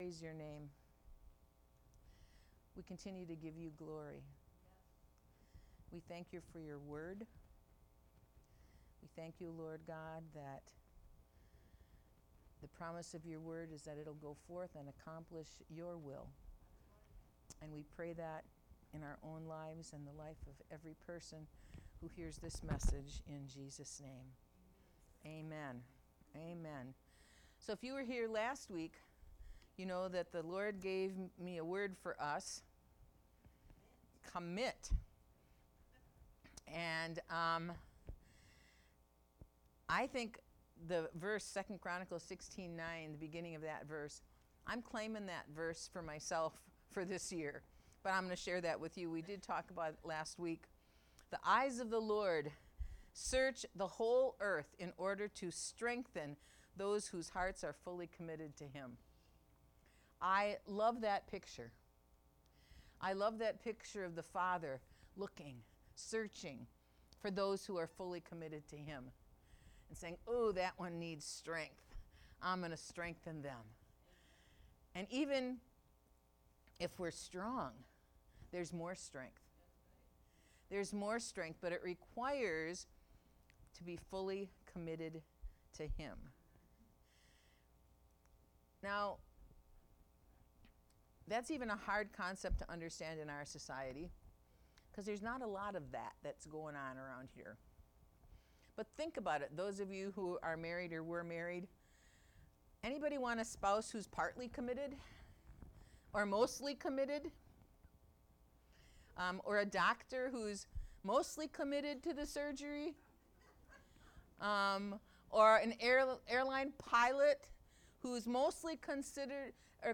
Sermons | The City of Hope